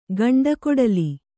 ♪ gaṇḍa kōḍali